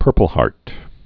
(pûrpəl-härt)